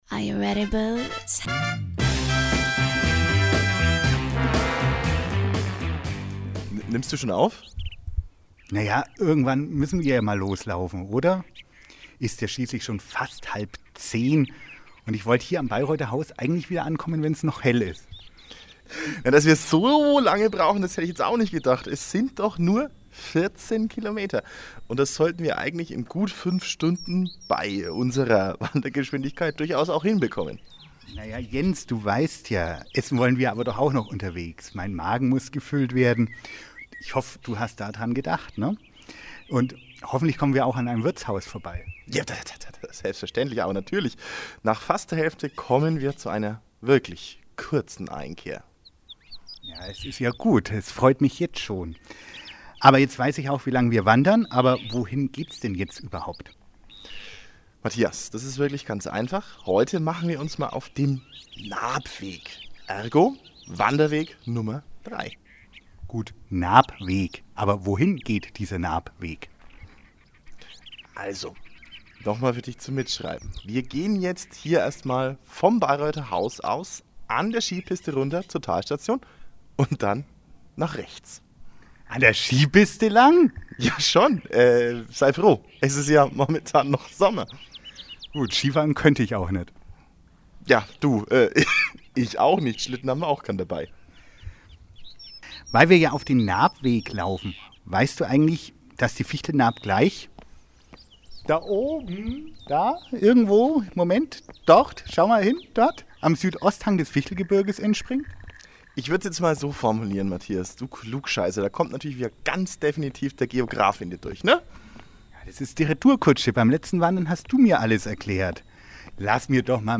Radio Mehlmeisel war für Sie unterwegs und hat den Naabweg bereits einmal erkundet. Hören Sie hier den Podcast der amüsanten Wanderung und erhalten Sie alle Infos zu den wichtigsten Stationen des Wanderweges.